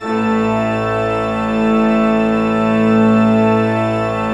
Index of /90_sSampleCDs/Propeller Island - Cathedral Organ/Partition F/MAN.V.WERK M